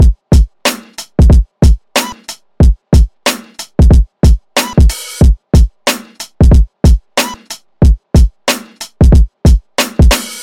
老式嘻哈鼓
描述：老式的嘻哈鼓，92 bpm
标签： 92 bpm Hip Hop Loops Drum Loops 1.76 MB wav Key : Unknown
声道立体声